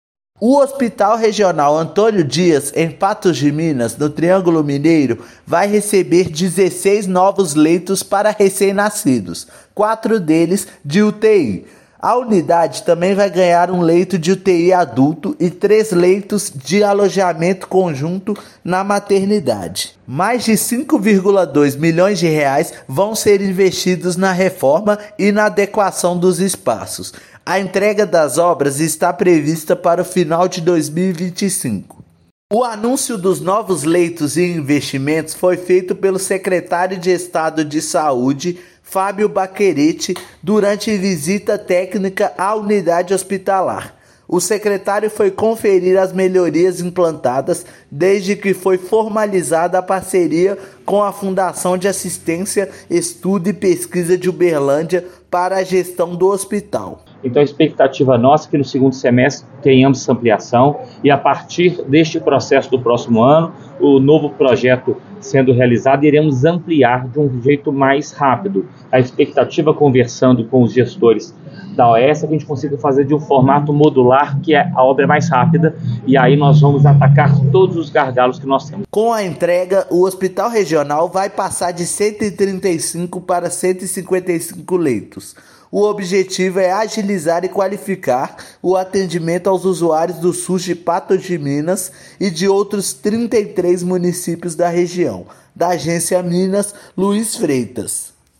Secretário de Estado de Saúde (SES-MG) e presidente da Fundação Hospitalar do Estado de Minas Gerais (Fhemig) vistoriaram a unidade para conferir as melhorias, após formalização de parceria na gestão. Ouça matéria de rádio.